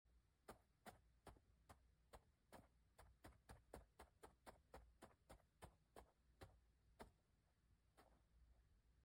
Upload By Foley Artist - Oddio Studio